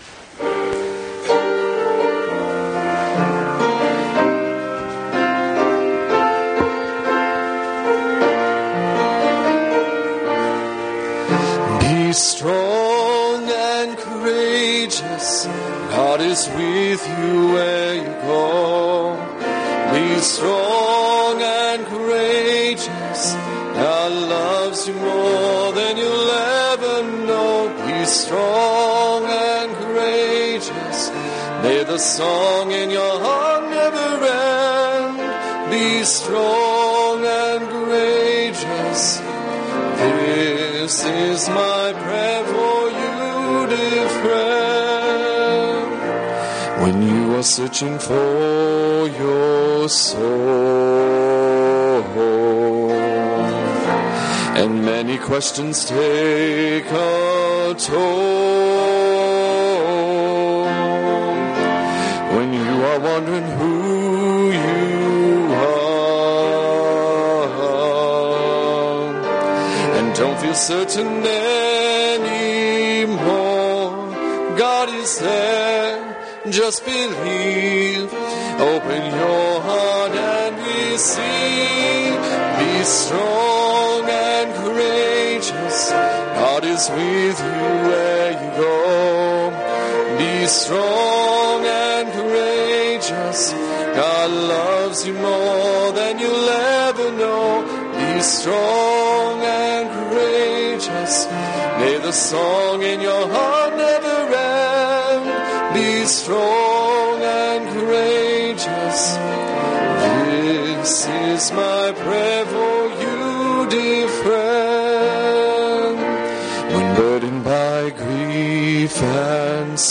congregation